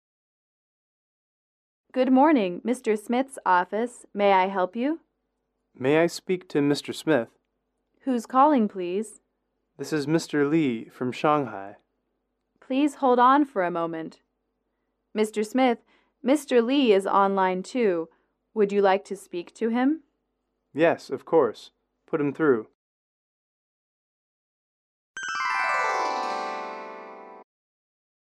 英语口语情景短对话57-3：转接电话（MP3）